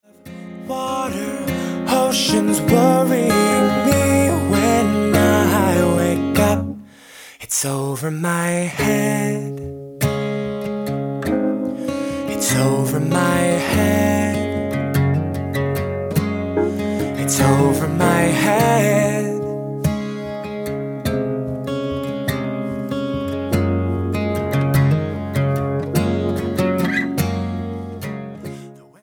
Roots/Acoustic